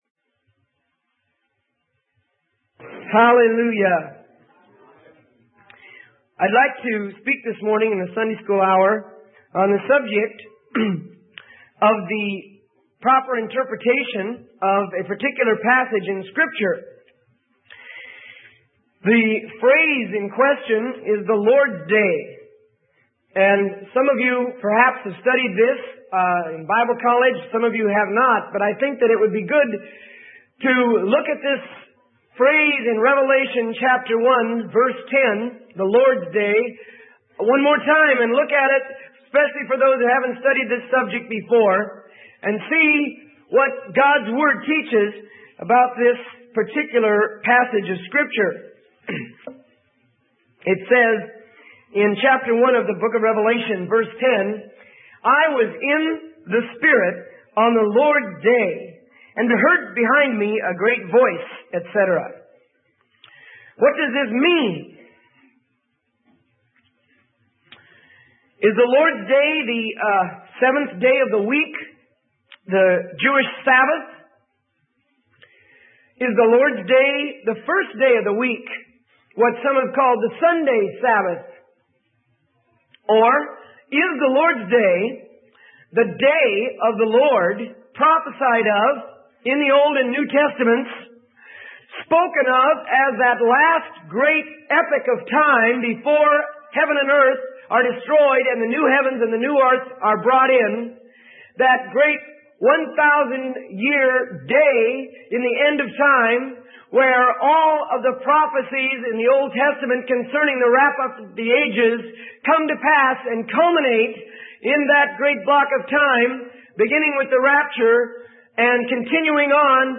Sermon: The Lord's Day - Freely Given Online Library